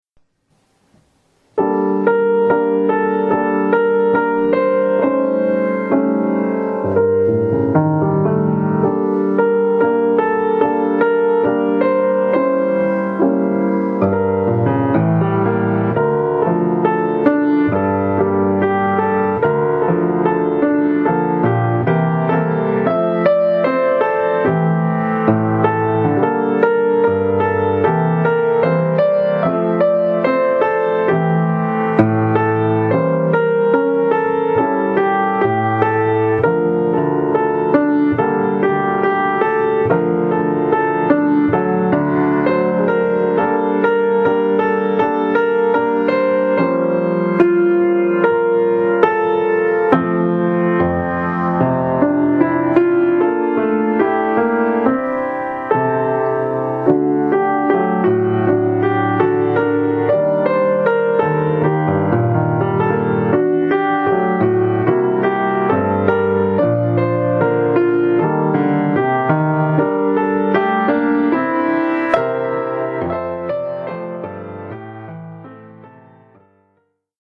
1 titre, piano solo : partie de piano
Oeuvre pour piano solo.